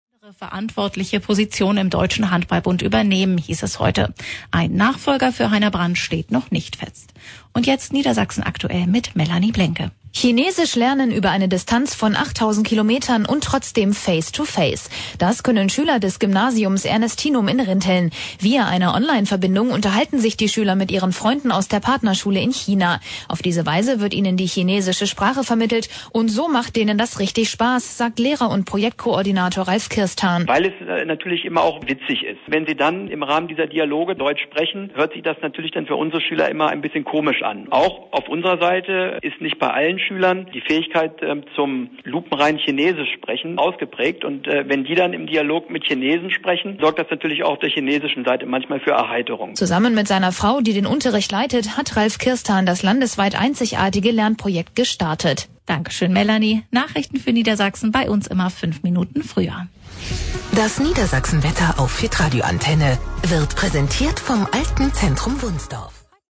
Radio Antenne Niedersachsen: Bericht über den deutsch-chinesischen Live-Online-Unterricht am Ernestinum